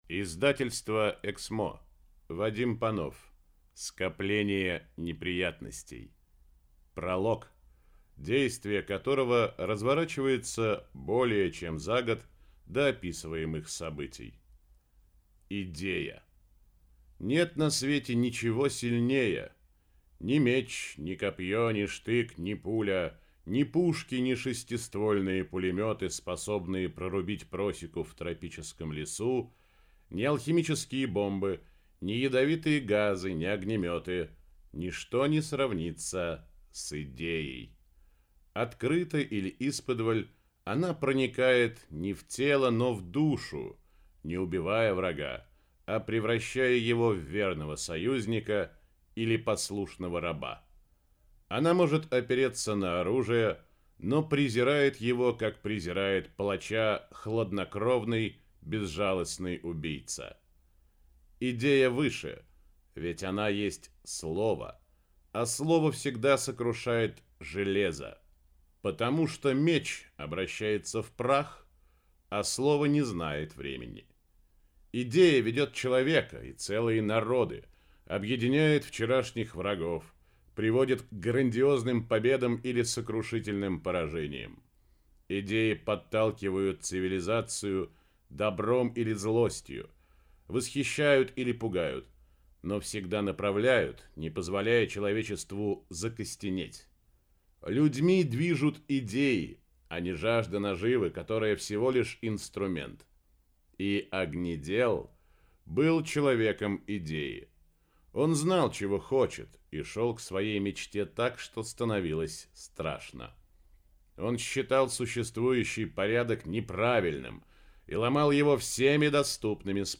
Аудиокнига Скопление неприятностей - купить, скачать и слушать онлайн | КнигоПоиск